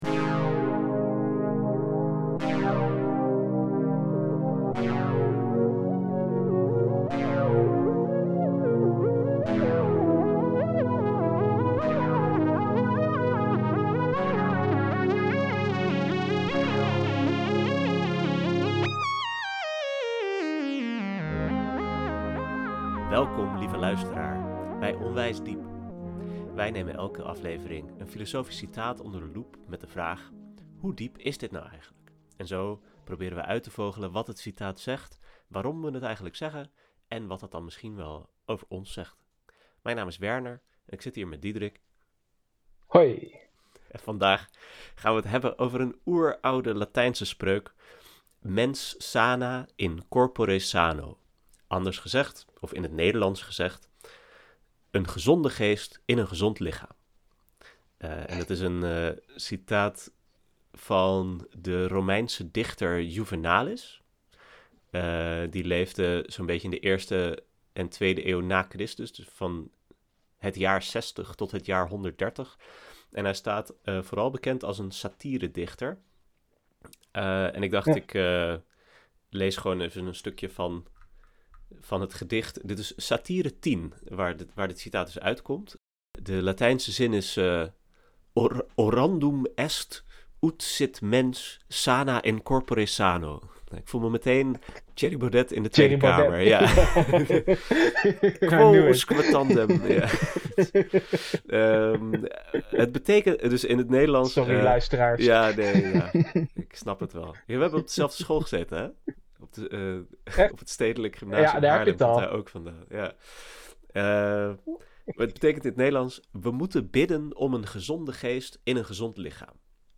Onwijs Diep is een podcast waarin twee vrienden wekelijks een diepzinnig citaat bespreken, om uit te vogelen wat het zegt, waarom we het zeggen en wat dat eigenlijk over ons zegt.